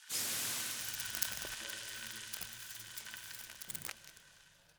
freeze 2.wav